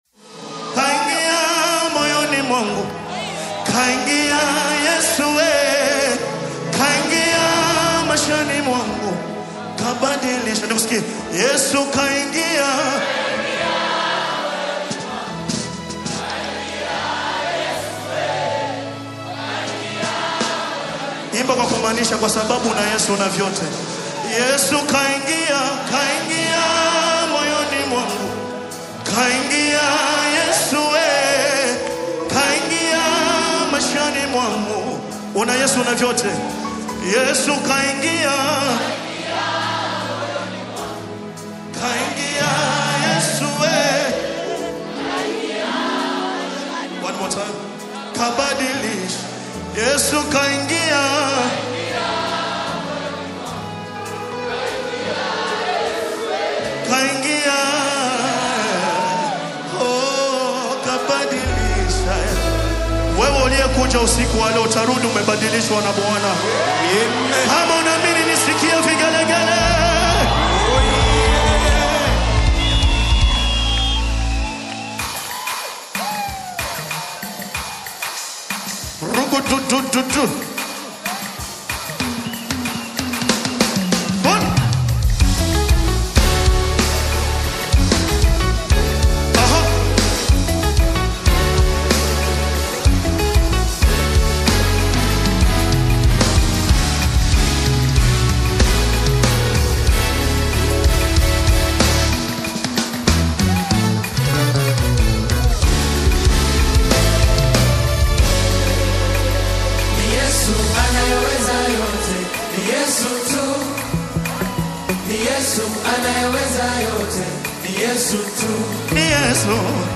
The rhythmically vibrant and vocally soulful single